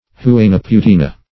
huainaputina.mp3